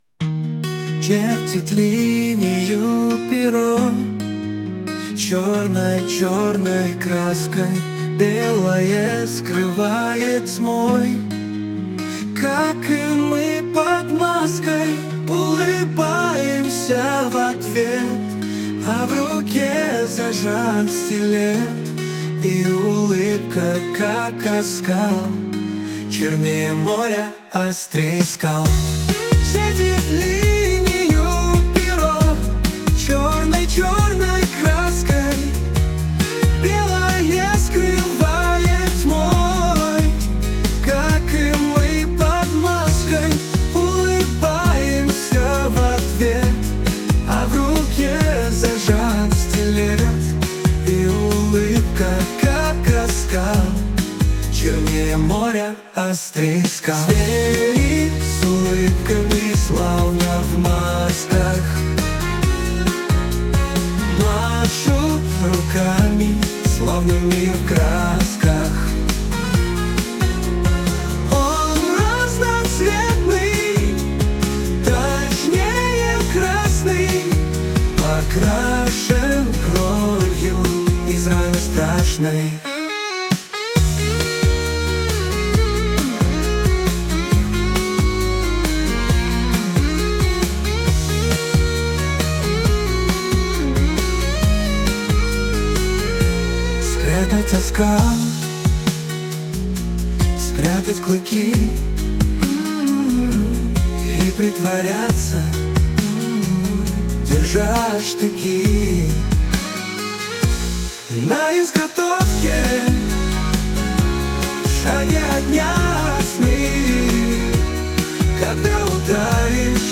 Psychedelic rock, tragic melodious melody